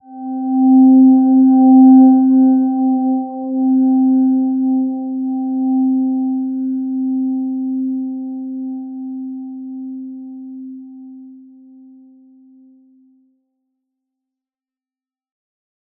Silver-Gem-C4-p.wav